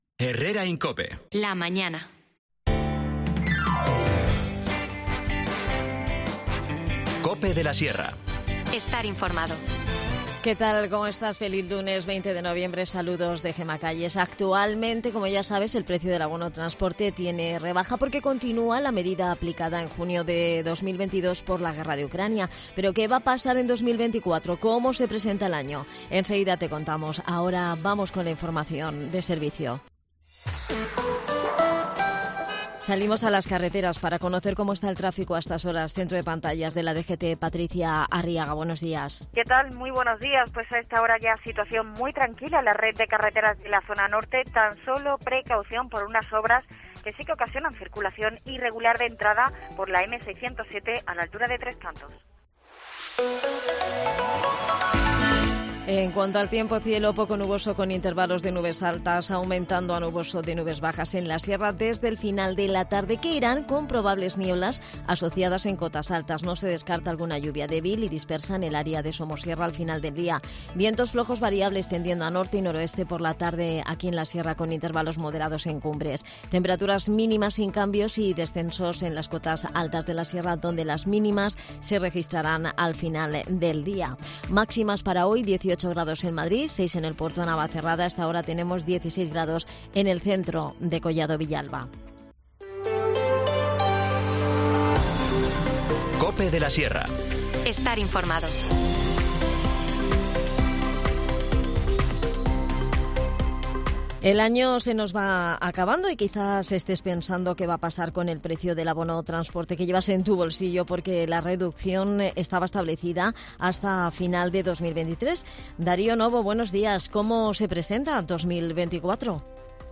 INFORMACIÓN LOCAL
Nos cuenta los detalles, Miguel Partida, concejal de Seguridad, Medio Ambiente y Mantenimiento Urbano.